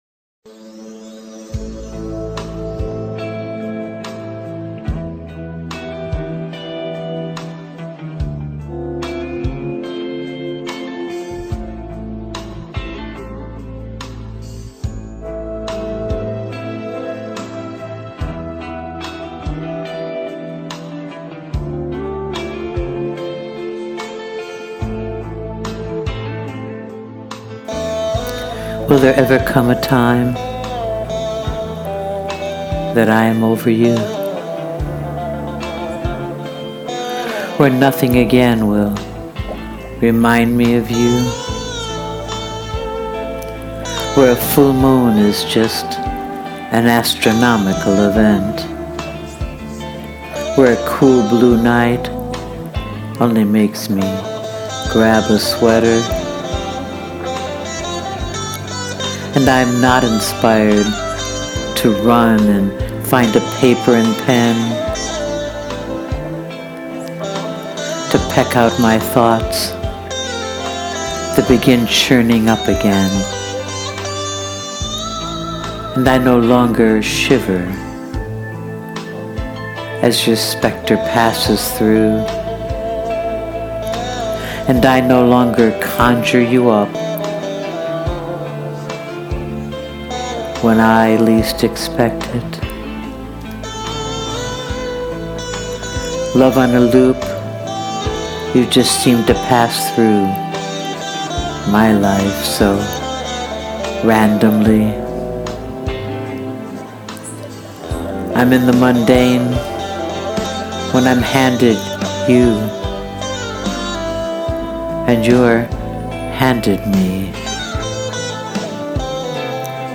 Another lovely and poignant one, voicing those words over that music, all so fitting.